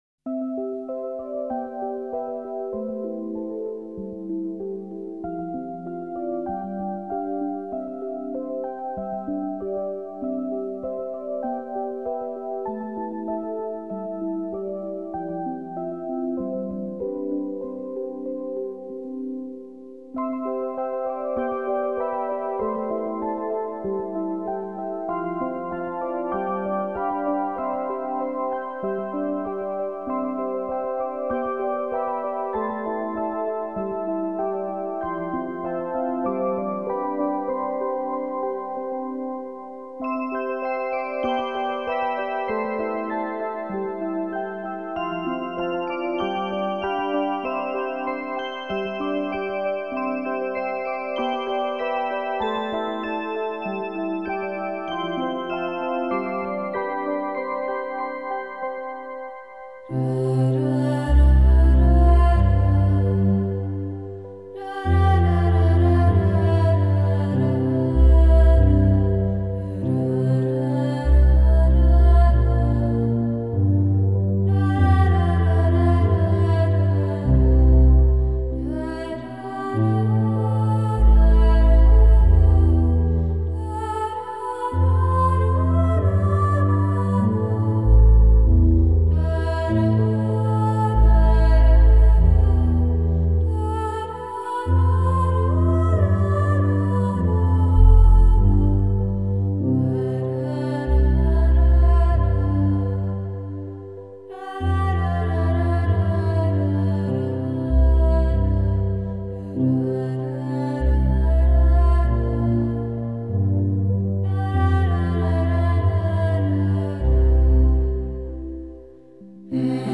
장르: Electronic
스타일: Modern Classical, Minimal, Ambient